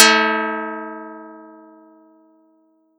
Audacity_pluck_7_13.wav